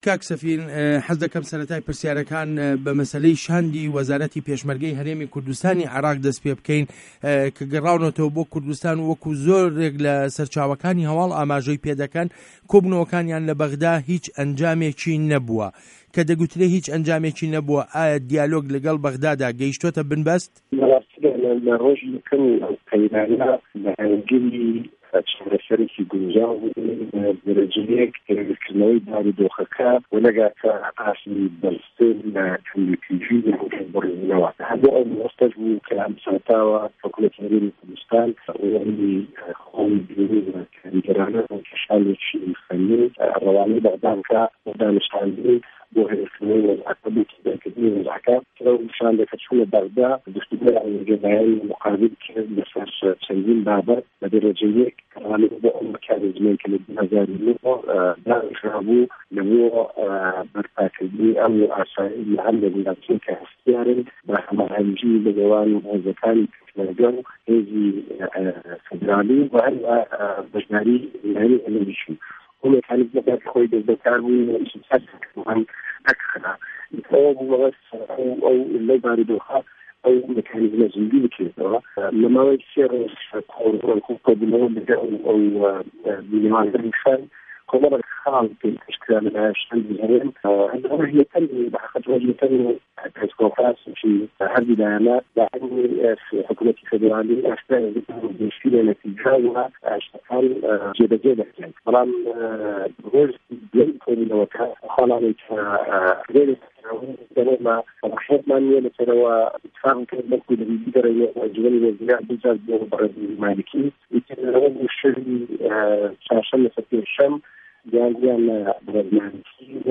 وتووێژ له‌گه‌ڵ سه‌فین دزه‌یی